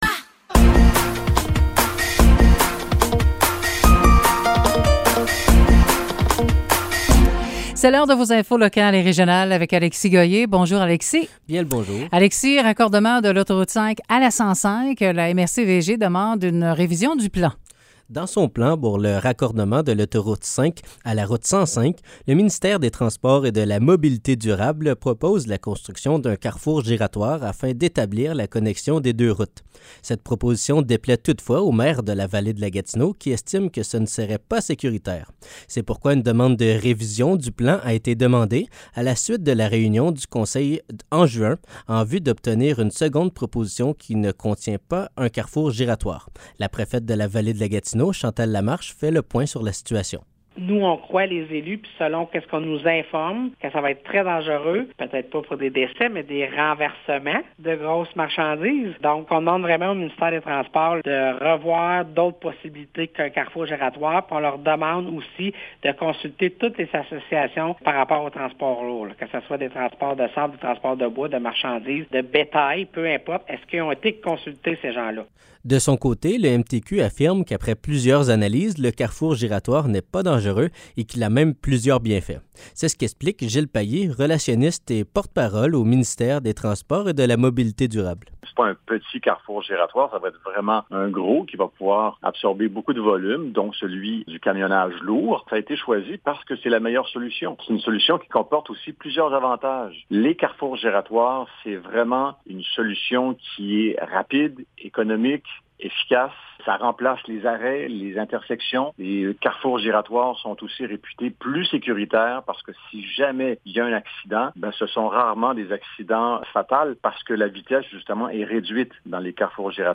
Nouvelles locales - 27 juin 2023 - 10 h